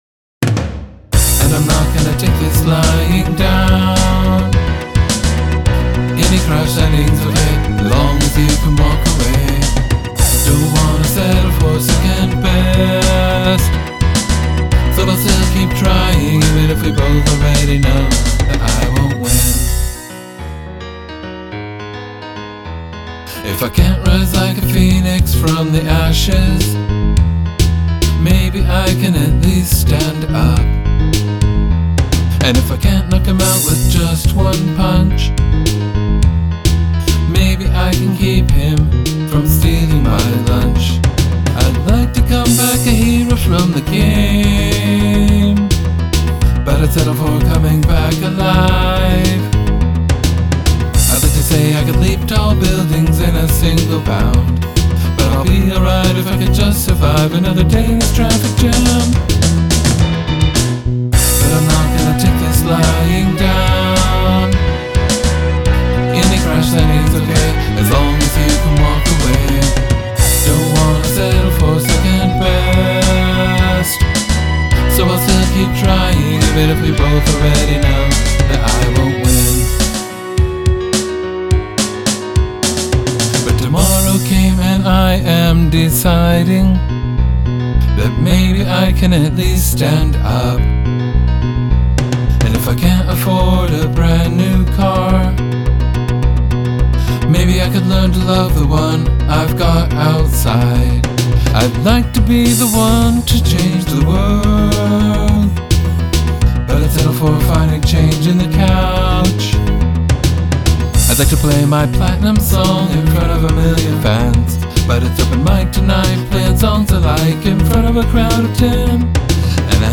My mixes tend to be more toward clear/brightish but not with much of any strong edge.